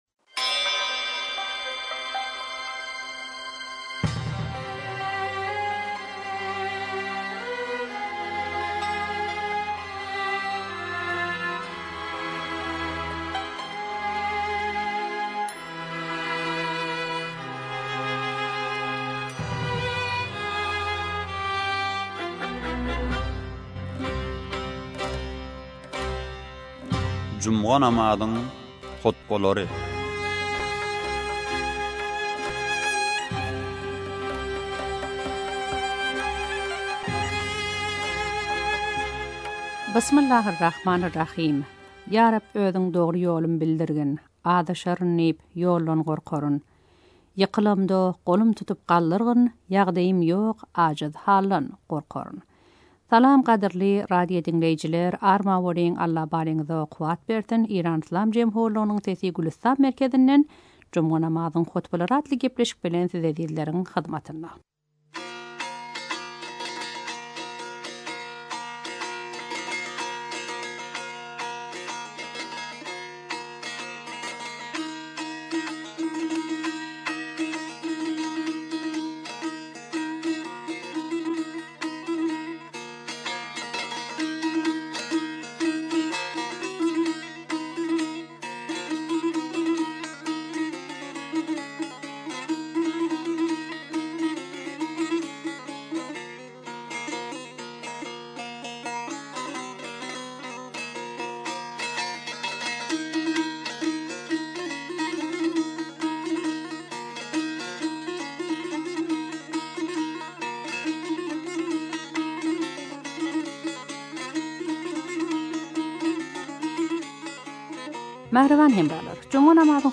juma namazyň hutbalary